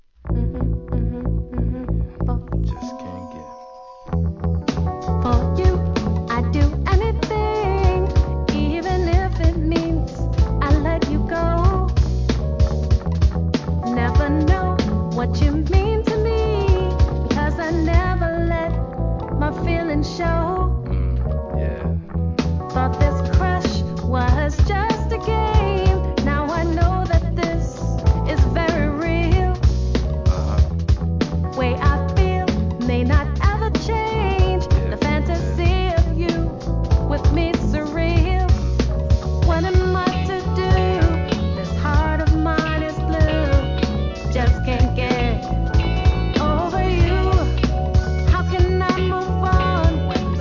2004年ネオ・ソウル!!